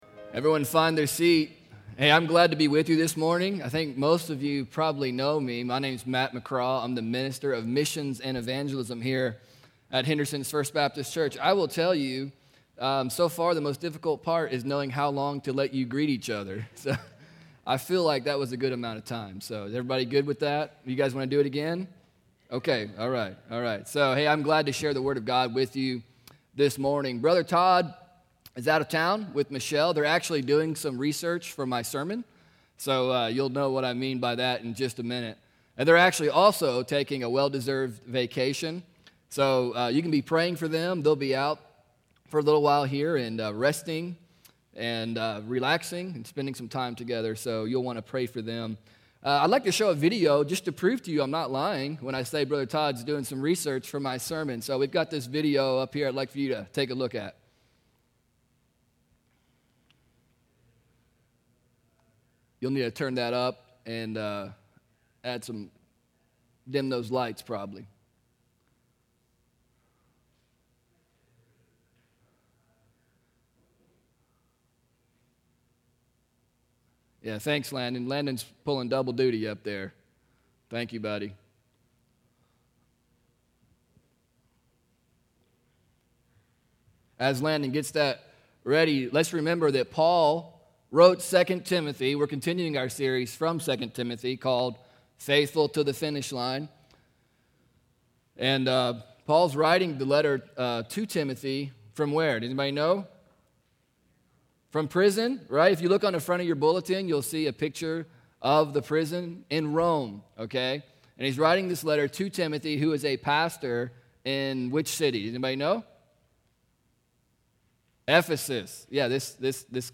Henderson’s First Baptist Church, Henderson KY Introduction to the message